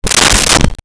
Close Door 02
Close Door 02.wav